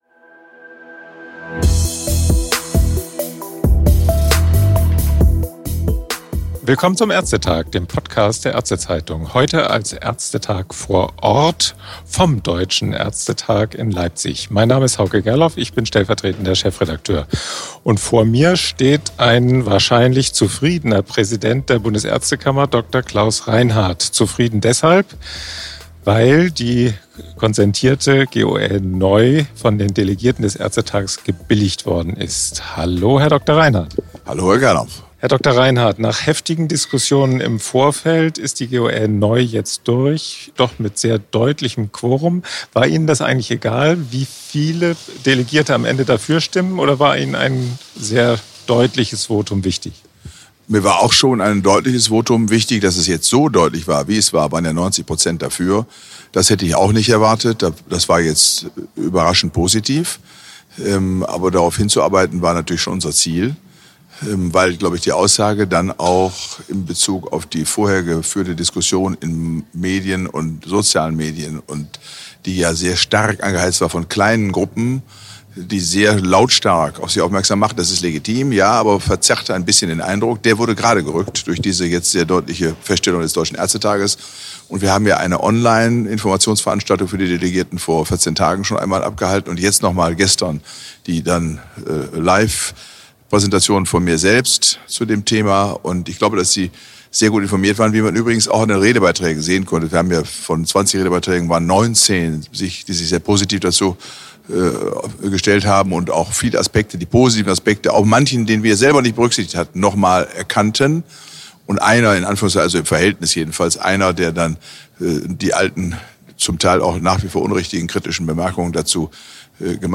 Das Ergebnis fiel mit 212 zu 19 Stimmen unerwartet klar aus – sehr zur Freude des Präsidenten der Bundesärztekammer Dr. Klaus Reinhardt, wie er im Podcast „ÄrzteTag vor Ort“ der Ärzte Zeitung erläutert.
Wir laden Gäste ein, mit denen wir über aktuelle Ereignisse aus Medizin, Gesundheitspolitik, Versorgungsforschung und dem ärztlichen Berufsalltag reden.